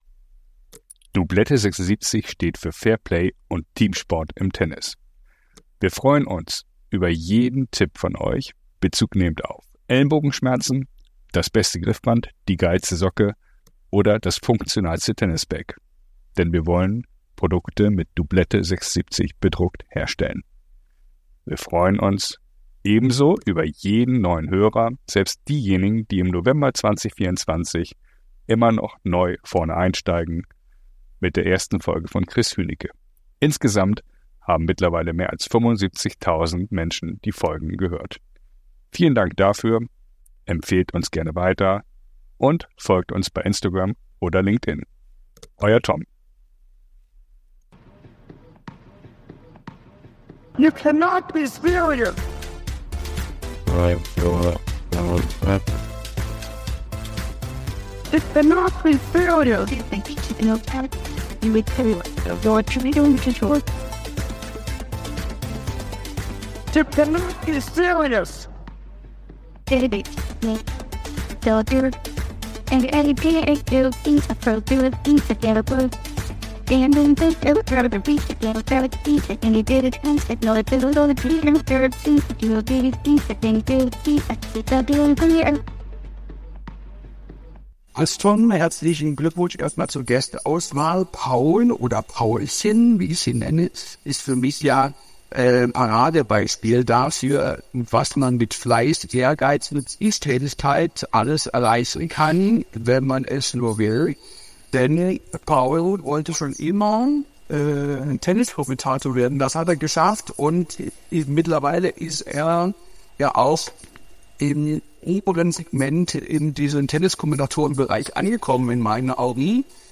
Warum er selbst seine Sportkarriere an den Nagel gehängt hat und wofür ihn die Zuschauer so schätzen, darüber spricht er bei Thorsten Otto.